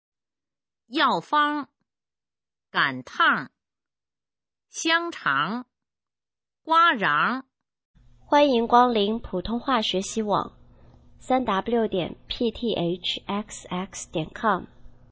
普通话水平测试用必读轻声词语表示范读音第51-100条